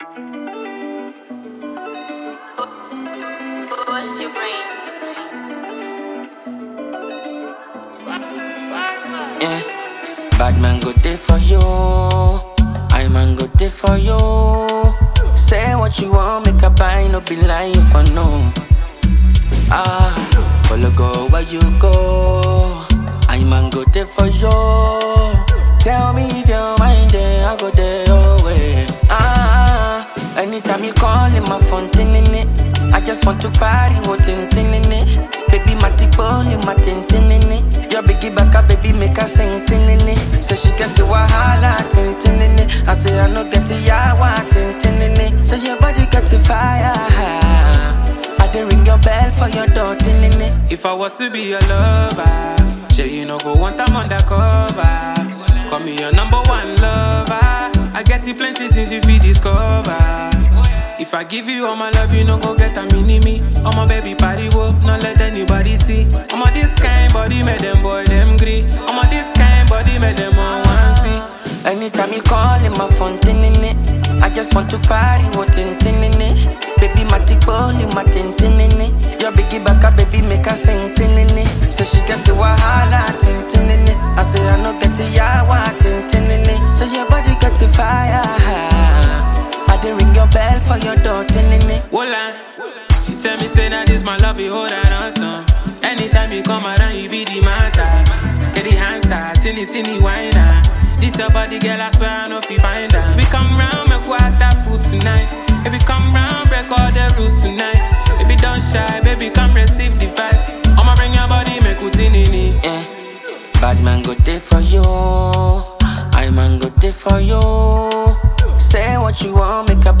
hot banger
afrobeat